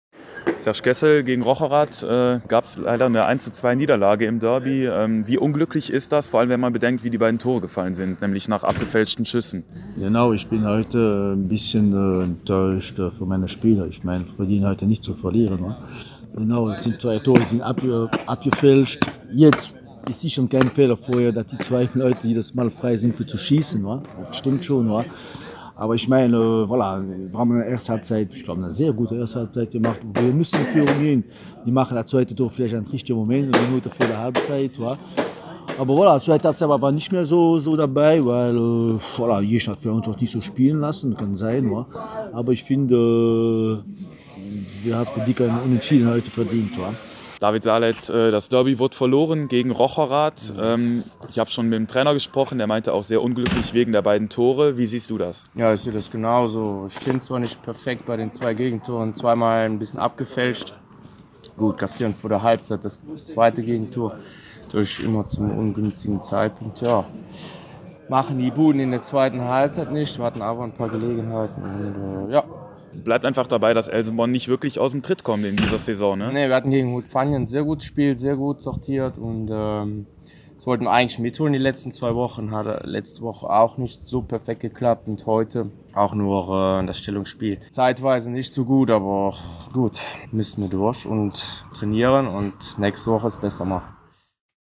vor Ort